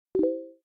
signal.ogg